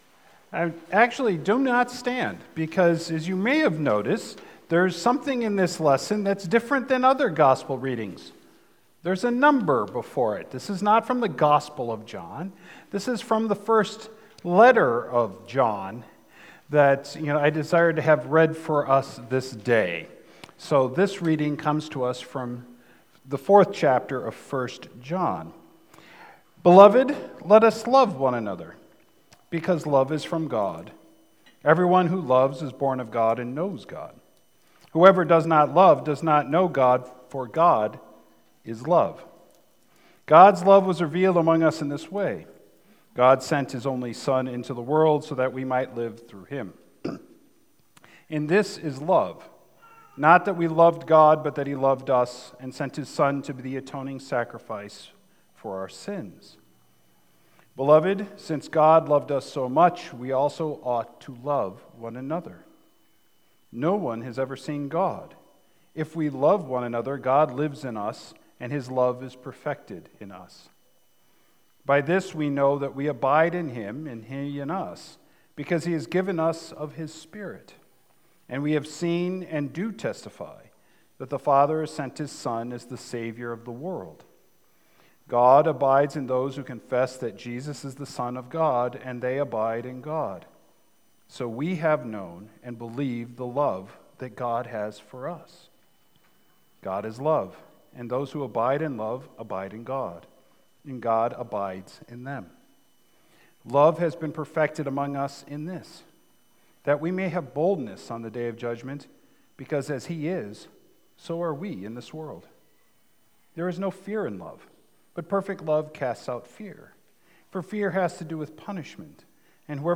Sermons | Beautiful Savior Lutheran Church